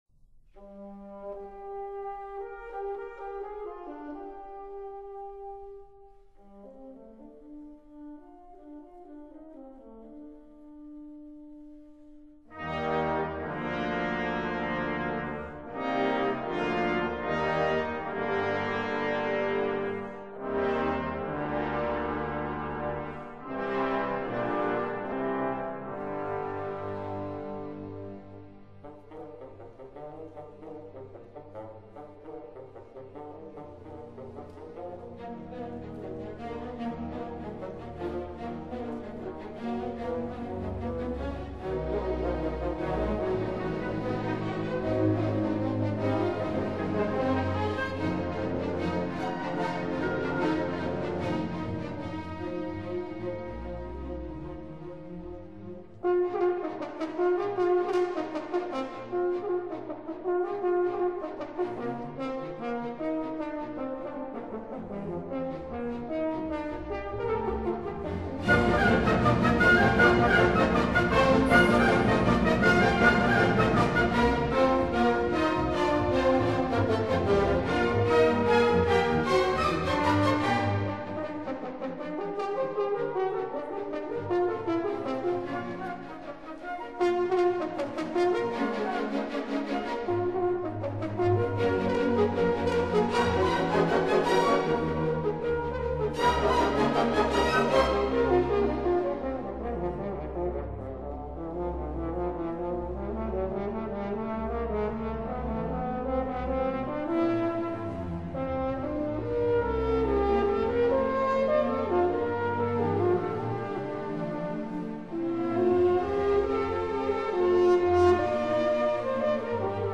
Moderato    [0:05:53.10]